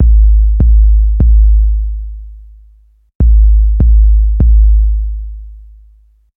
Trill Bass Kick Loop 1
描述：轰隆隆，咚咚咚，808's
Tag: 150 bpm Hip Hop Loops Drum Loops 1.08 MB wav Key : Unknown